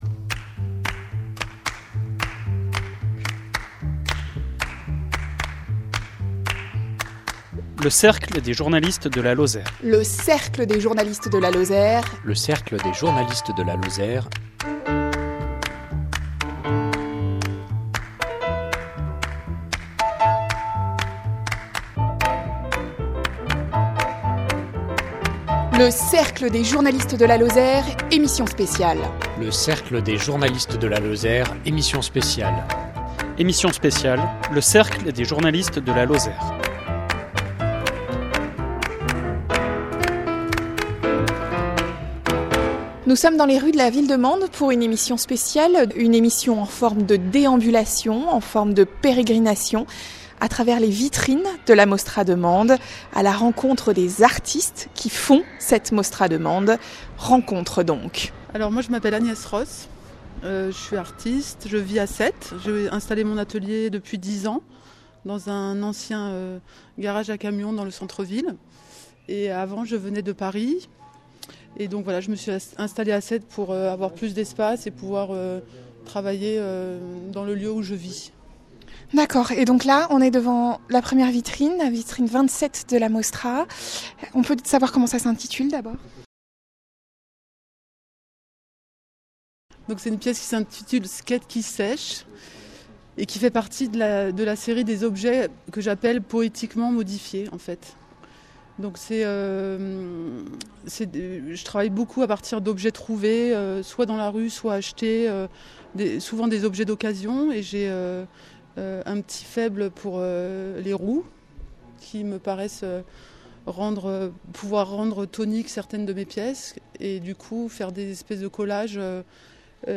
Un reportage dans les rues de Mende, devant les vitrines de la Mostra, par :